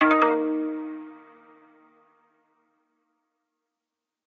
reminder.ogg